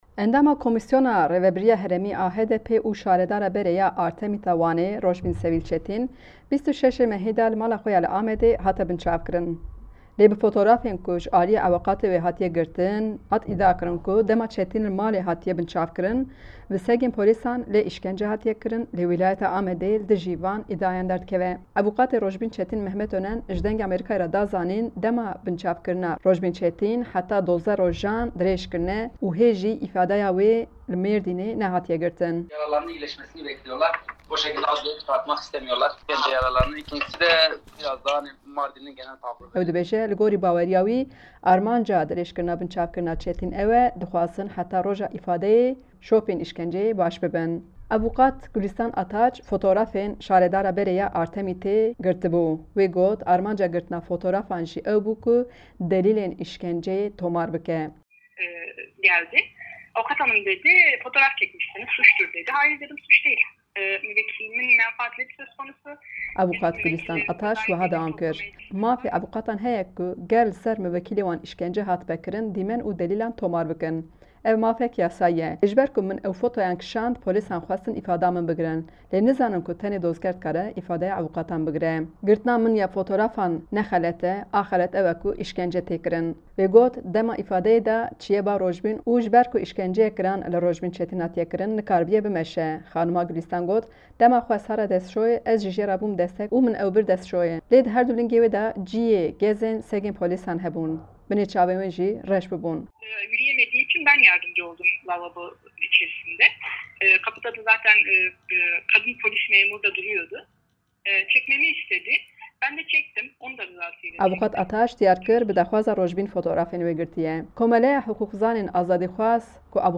Raporta Amedê